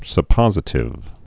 (sə-pŏzĭ-tĭv)